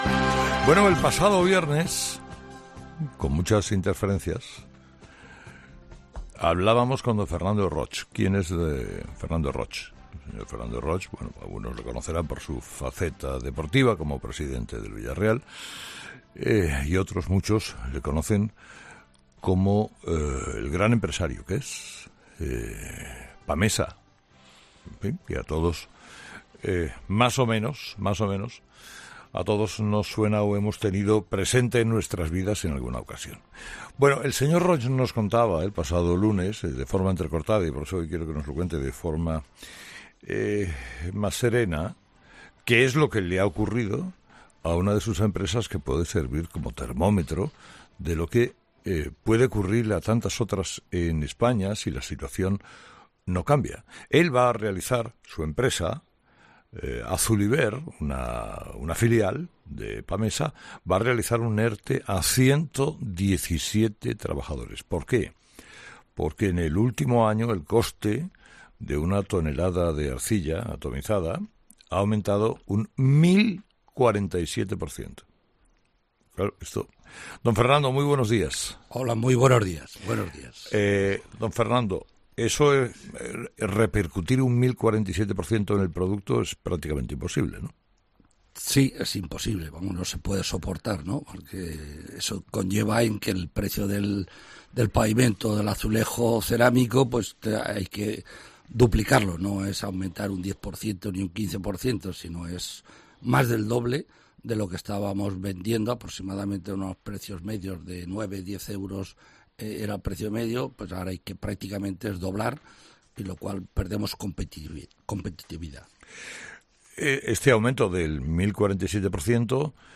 El presidente y dueño de Pamesa explica en COPE los motivos por los que ha tenido que hacer un ERTE a 117 trabajadores de esta filial